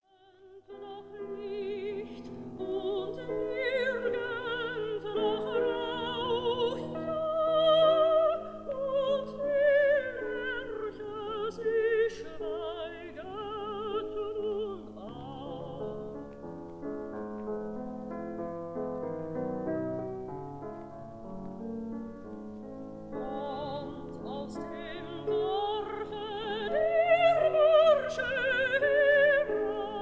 Sofiensaal, Vienna